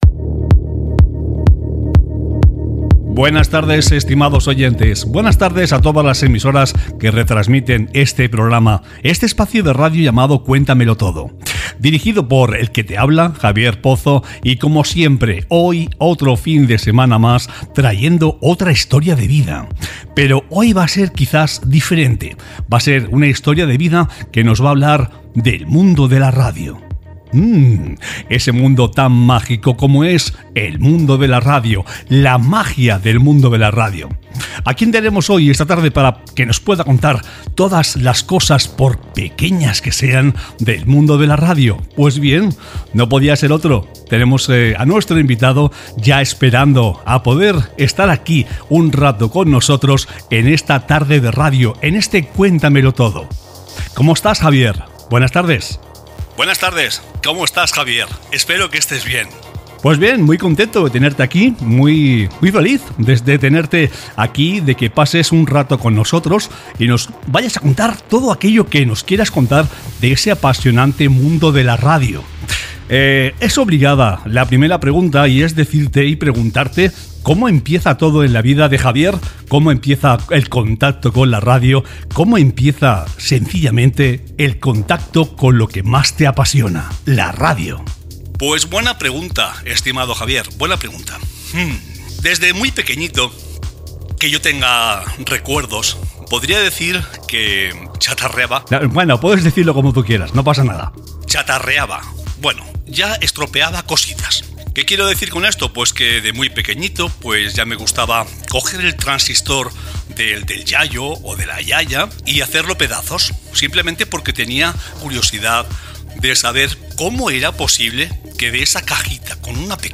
Salutació i autoentrevista